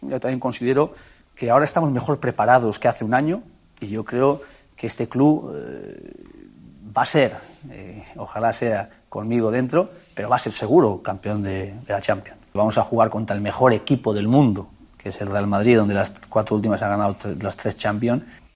Entrevista en 'Universo Valdano'